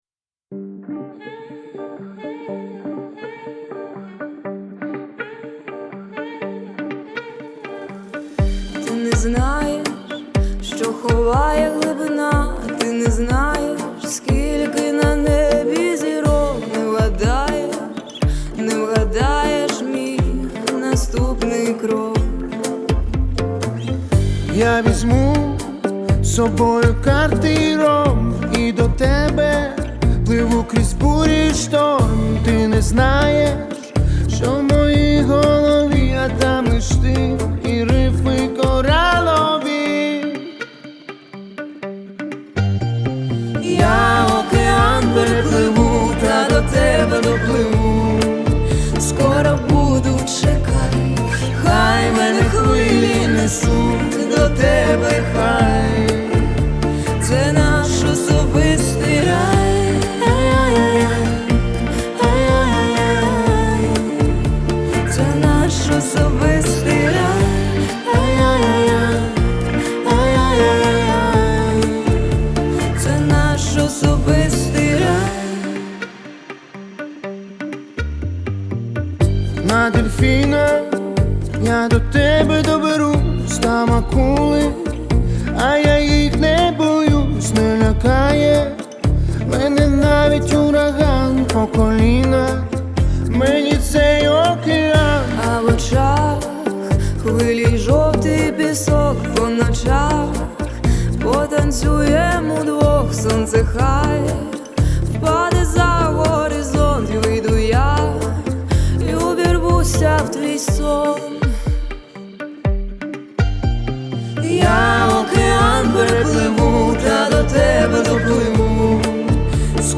це ніжна і водночас пристрасна пісня в стилі бачата